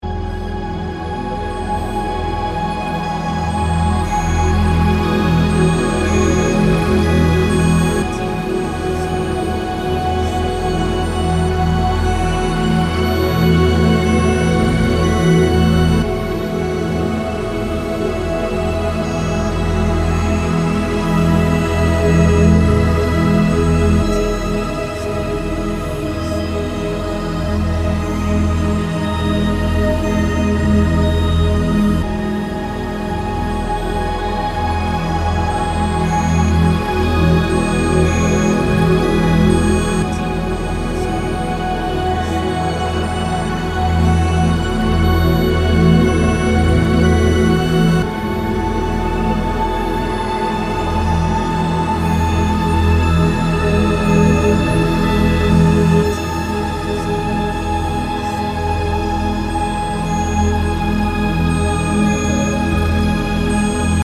Misterius tapi damai.